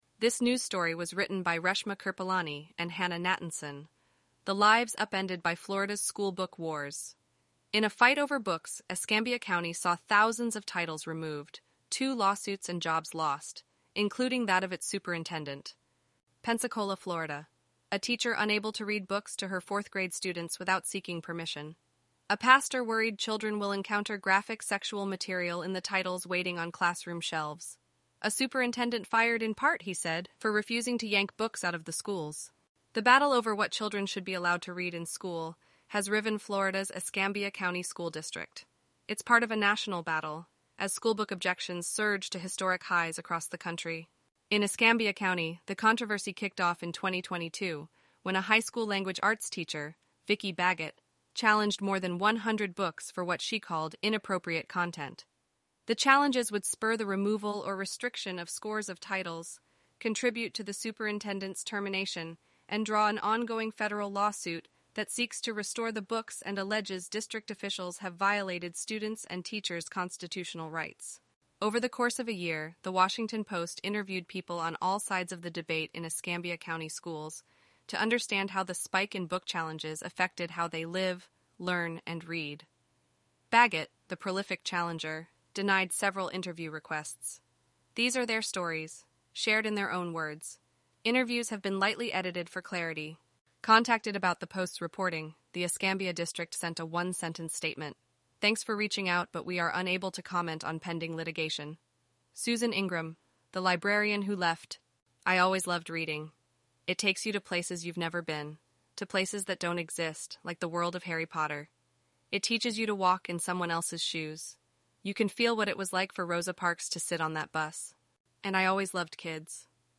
eleven-labs_en-US_Rachel_standard_audio.mp3